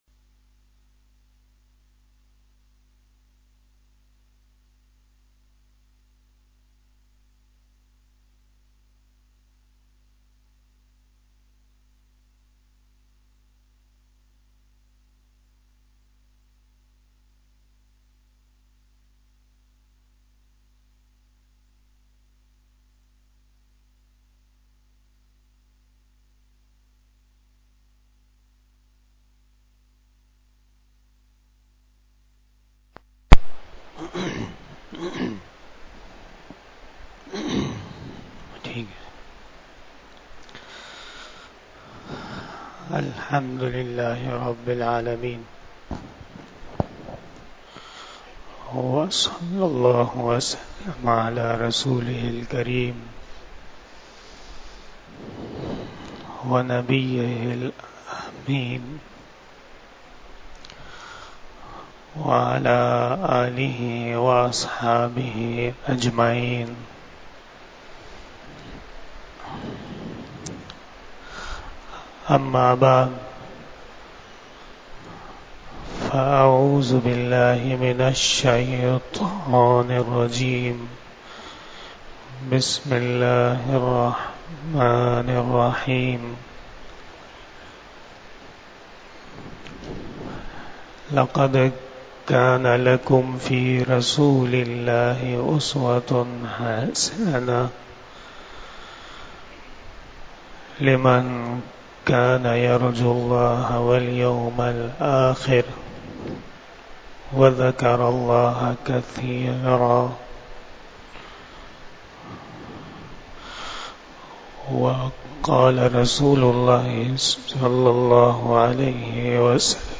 37 BAYAN E JUMMAH 22 September 2023 ( 05 Rabi ul Awwal 1445HJ)